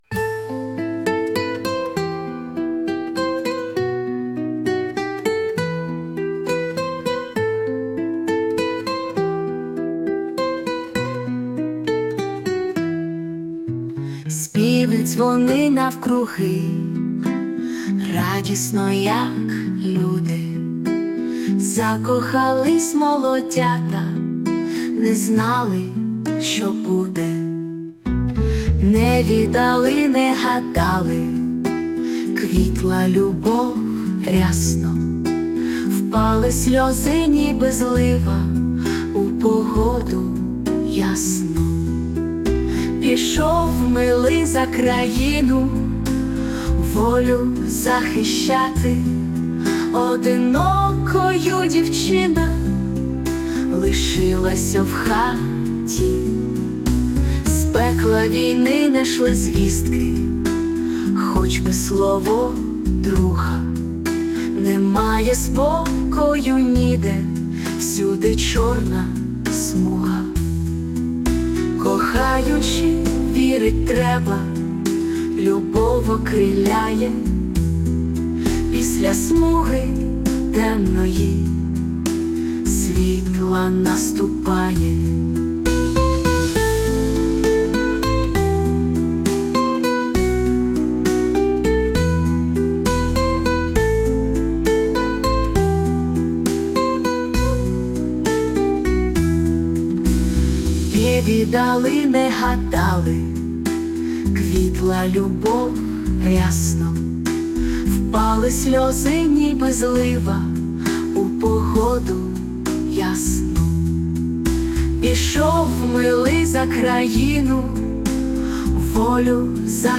Музична композиція ШІ
ТИП: Пісня
СТИЛЬОВІ ЖАНРИ: Ліричний
як давня народна пісня....щасти вам!